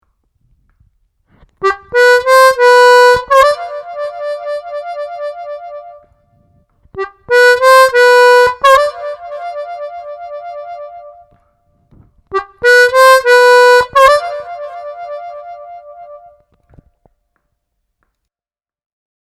Сыграть музыкальную фразу с трелью -2 -3 +4 -3 -(45)